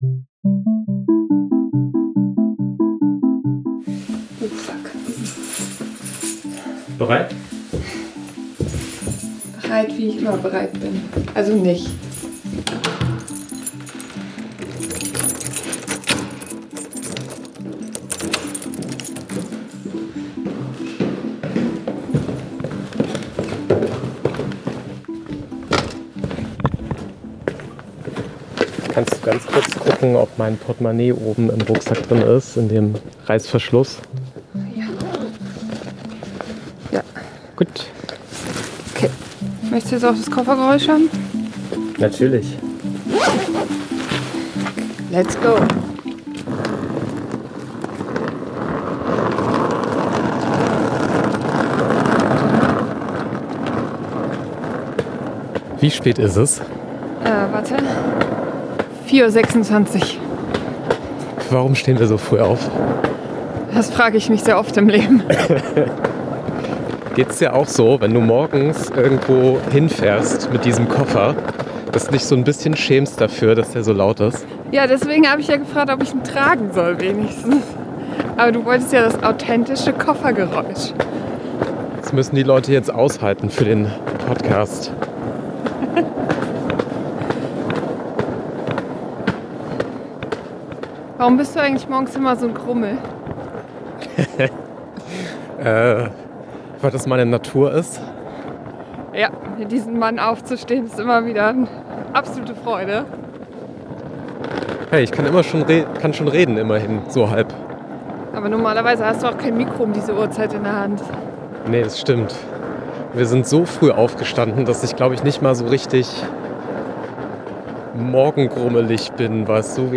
Reisereportage meets Konferenzbericht. Koffergeräusche inklusive.